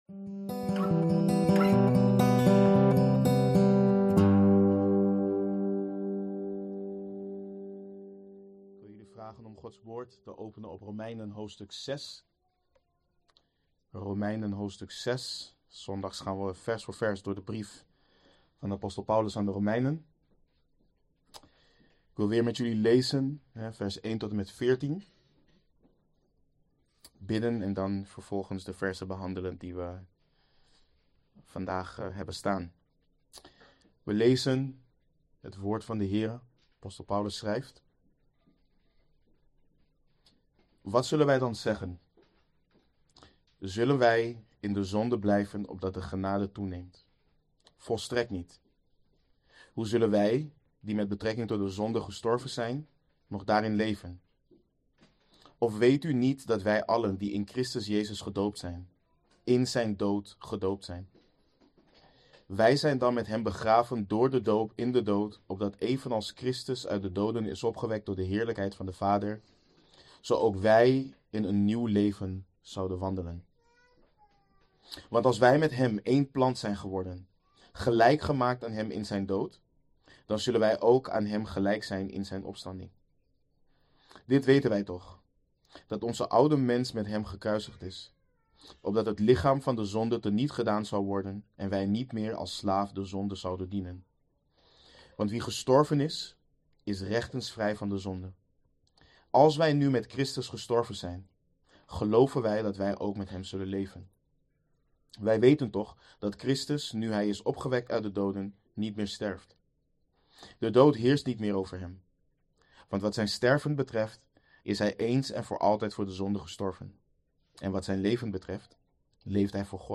Verklarende prediking.